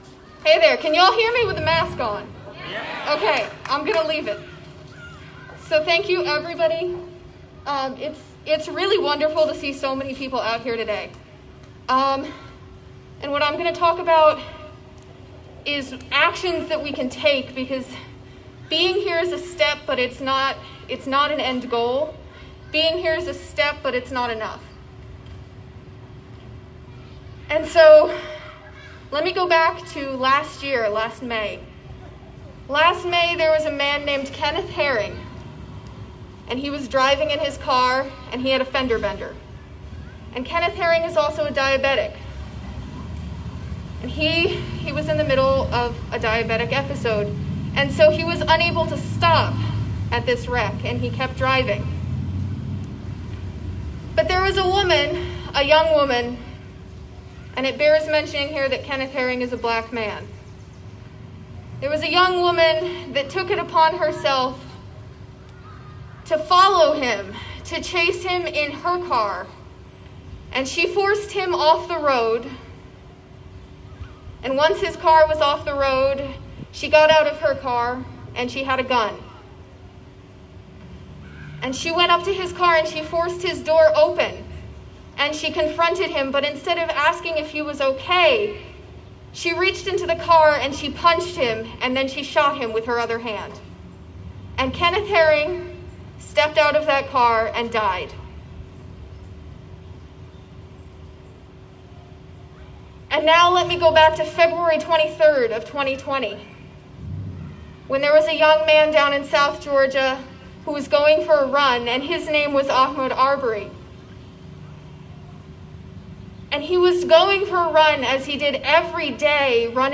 lifeblood: bootlegs: 2020-06-14: peace and unity rally at hancock park - dahlonega, georgia (amy ray)
(captured from a facebook livestream)